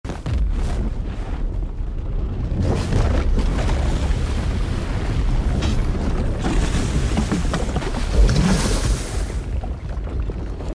The sound of a volcano sound effects free download
*Eruption in Iceland